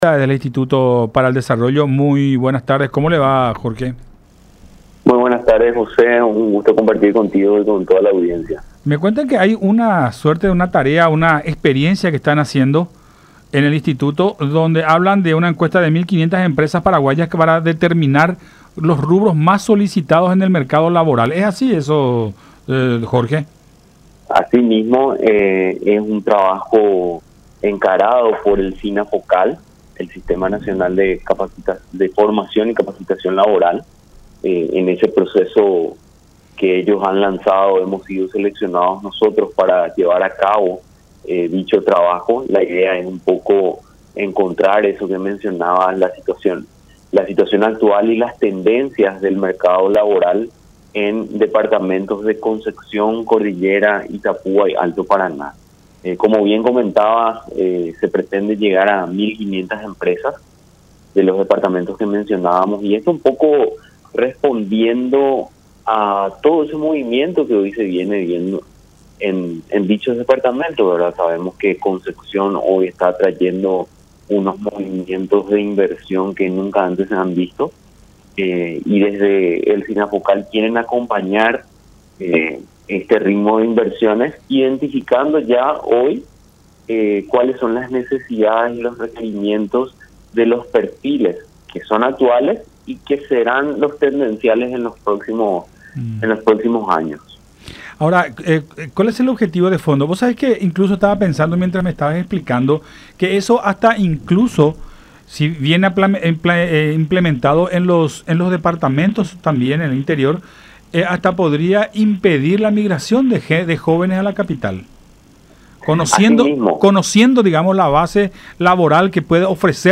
en diálogo con Buenas Tardes La Unión